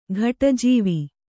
♪ ghaṭa jīvi